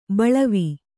♪ baḷavi